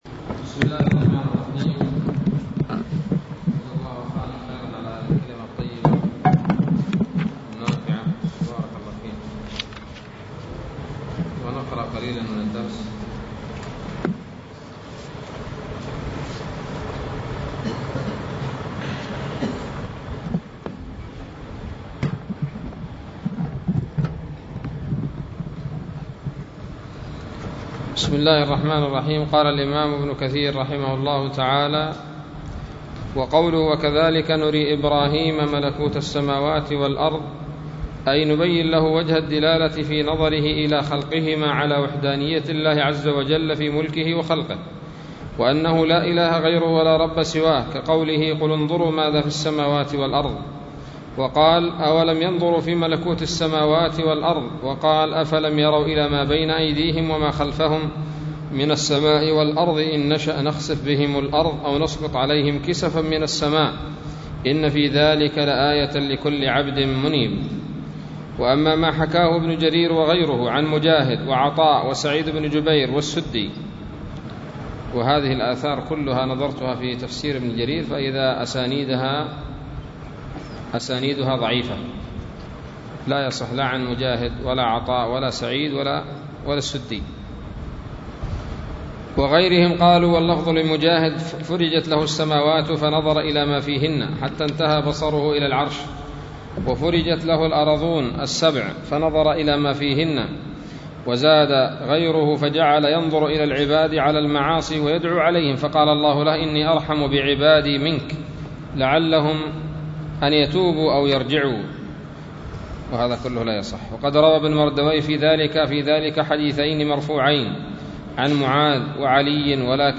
الدرس الثامن والعشرون من سورة الأنعام من تفسير ابن كثير رحمه الله تعالى